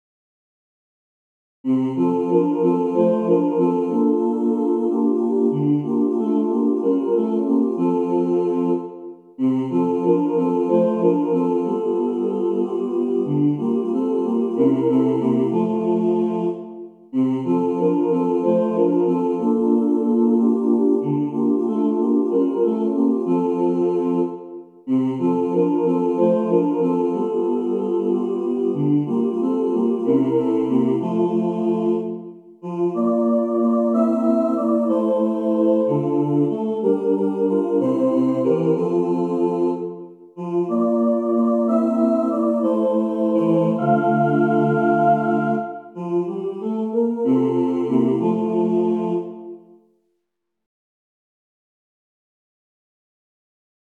A Cappella/Optional A Capella